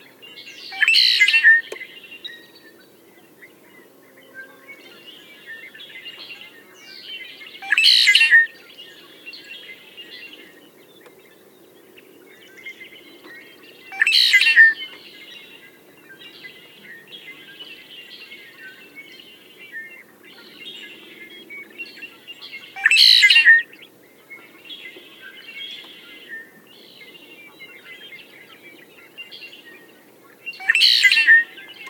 The undergrowth has been allowed to grow under some of the pylons, producing a useful habitat for all kinds of birds, especially the omnipresent red-winged blackbirds.
And here’s a red-winged blackbird…
Red-winged blackbird